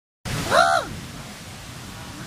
Vogel_Ruf.mp3